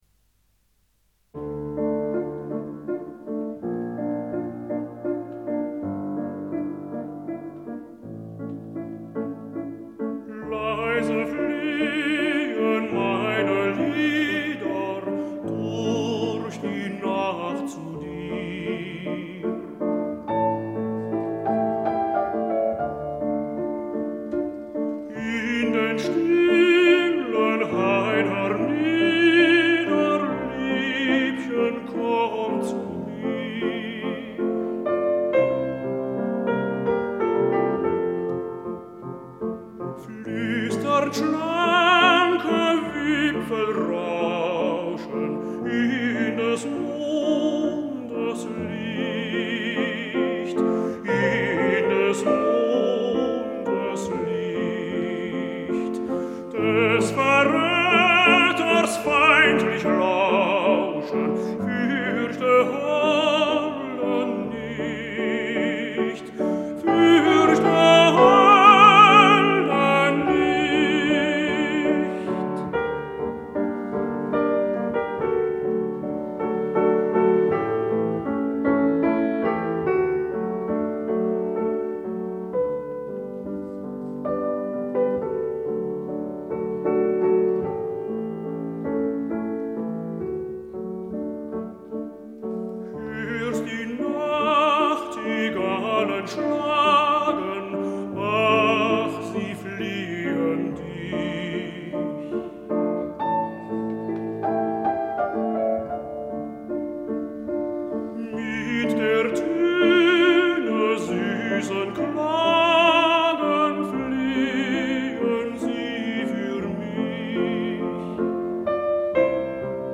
男 高 音
钢 琴